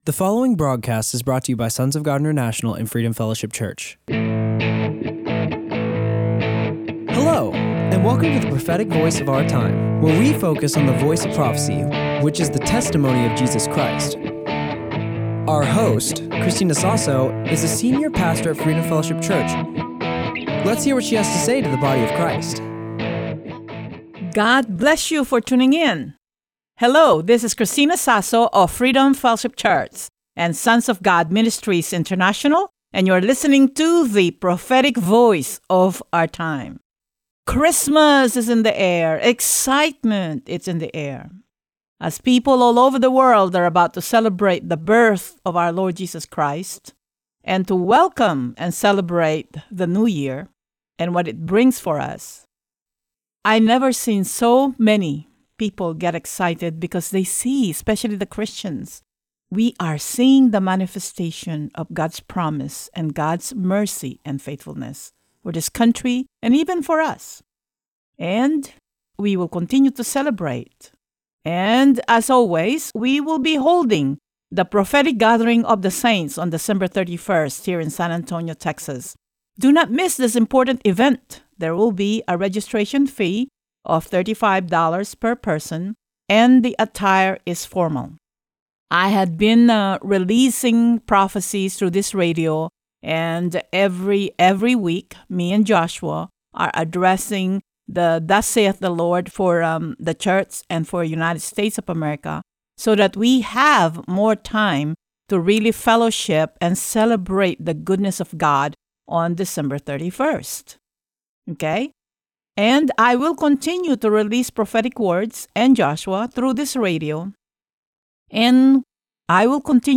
The Prophetic Voice of Our Time is a weekly radio show